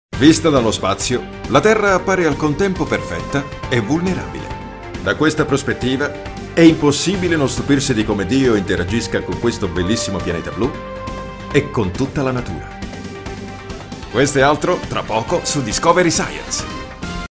Italian Speaker, Italian Voice over talent, middle/old voice
Sprechprobe: Sonstiges (Muttersprache):
My voice has a low tone from warm tone and is suitable for commercials institutional advertising and so on, answering machines, documentaries, jingles, audio books, audio guidance, e-learning, voice over, multimedia audio voiceovers